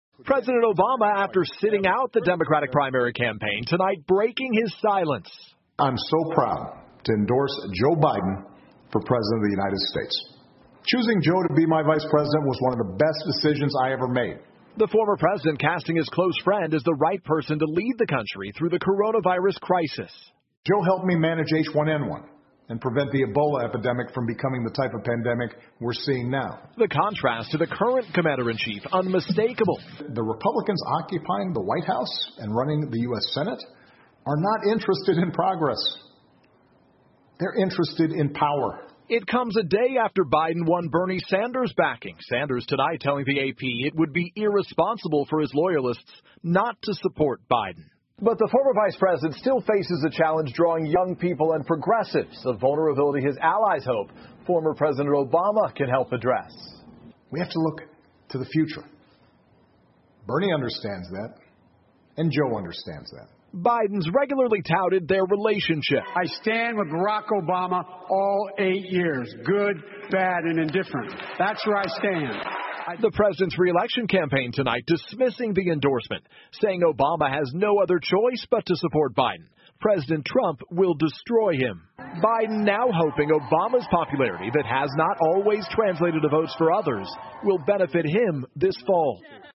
NBC晚间新闻 奥巴马宣布支持拜登 听力文件下载—在线英语听力室